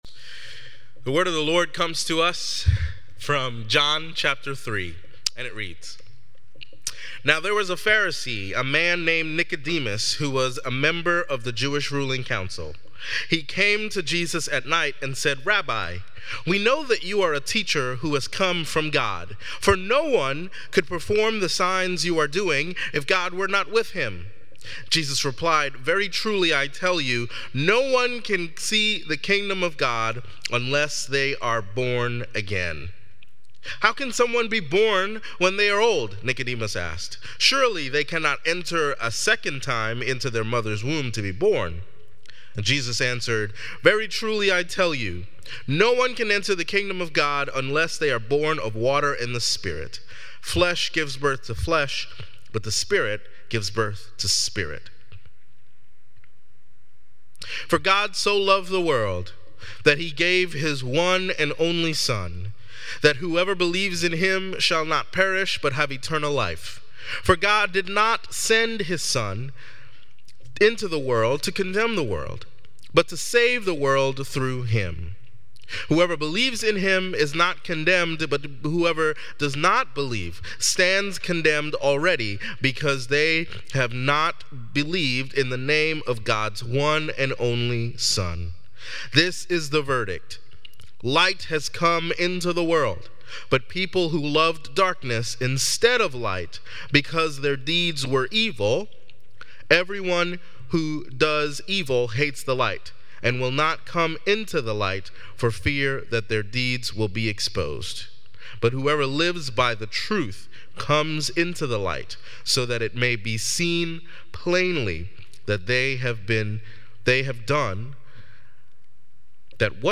Sermon Reflections: In the story of Nicodemus, how does the concept of "light vs. darkness" play out?